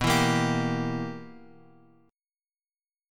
BM7sus4#5 chord